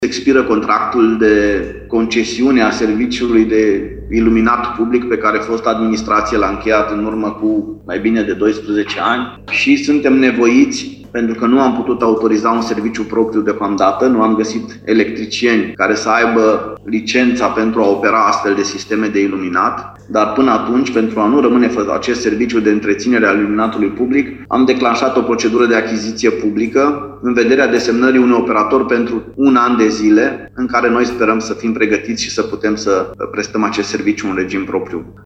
Primăria Lugoj se pregătește să organizeze o licitație publică pentru găsirea altui operator care să întrețină iluminatul pe străzile din oraș, spune primarul, Claudiu Buciu.